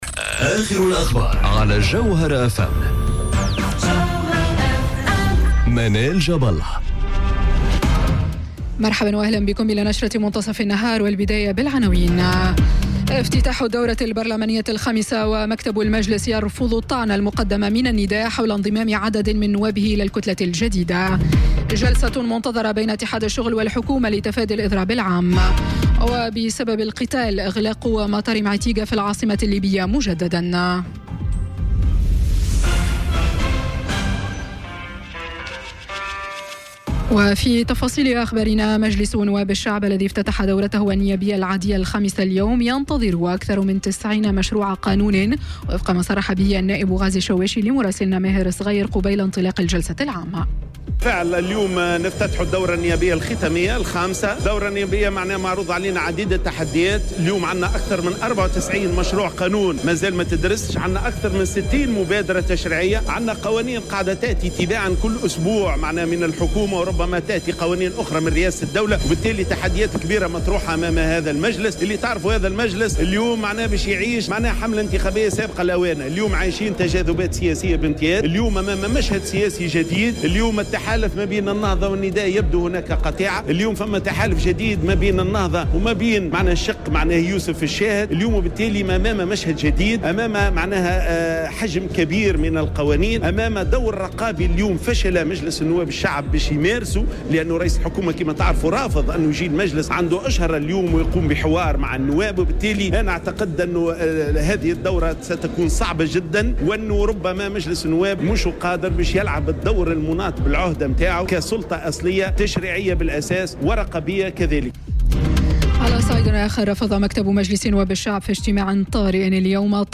نشرة أخبار منتصف النهار ليوم الثلاثاء 02 أكتوبر 2018